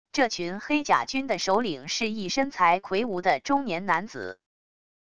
这群黑甲军的首领是一身材魁梧的中年男子wav音频生成系统WAV Audio Player